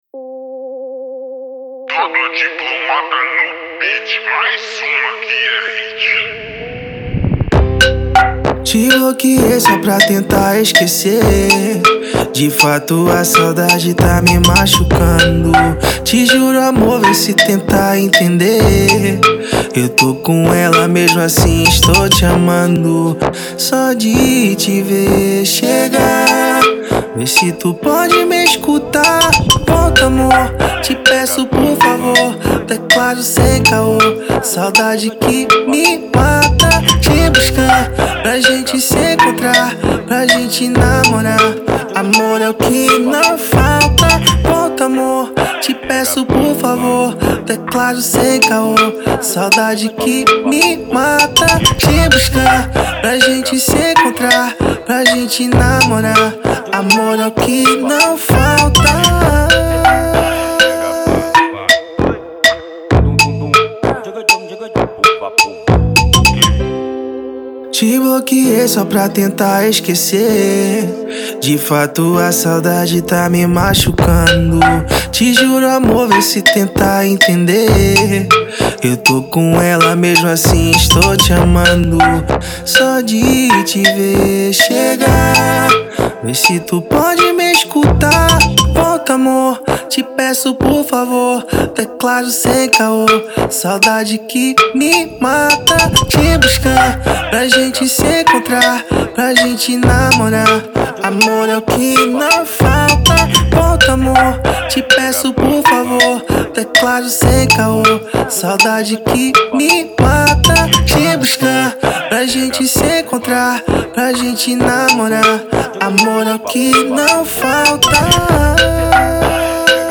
EstiloBrega Funk